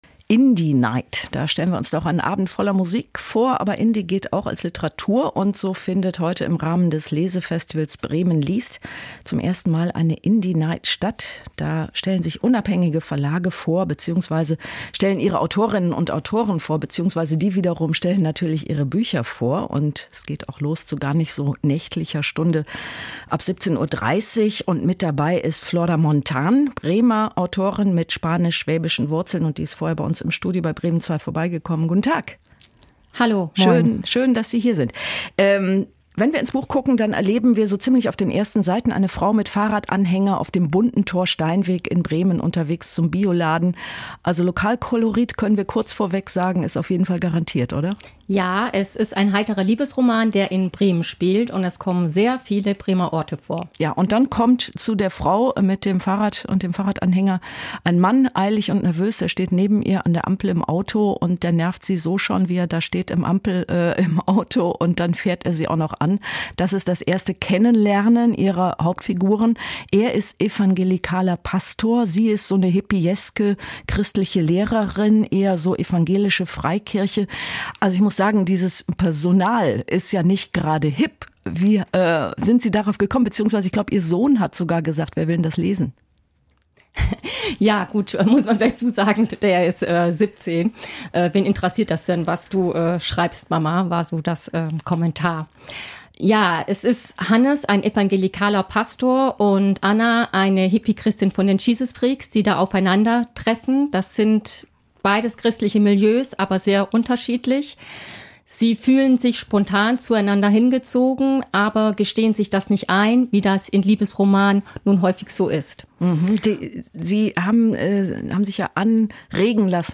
Interview-Radio-Bremen-Zwei-23-9-2.mp3